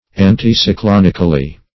-- An`ti*cy*clon"ic , a. -- An`ti*cy*clon"ic*al*ly , adv.
anticyclonically.mp3